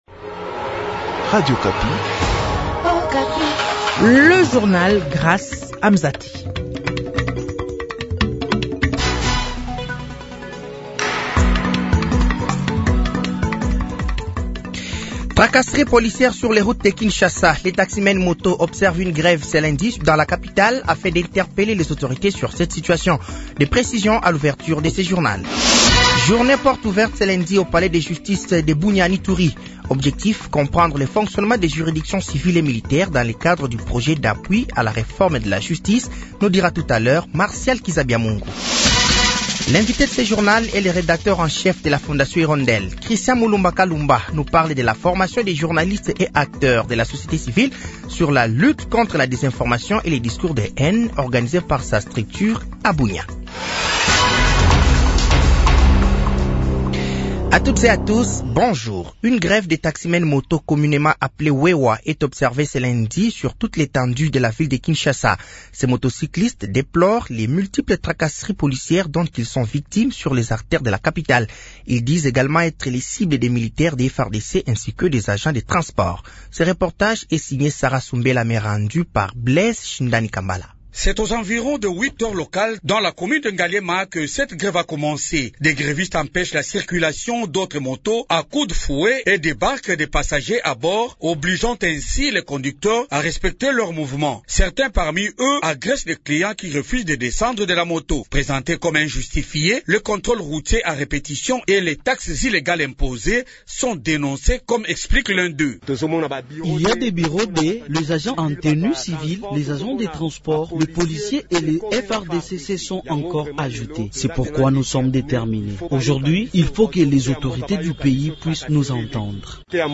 Journal de 15h
Journal français de 15h de ce lundi 30 septembre 2024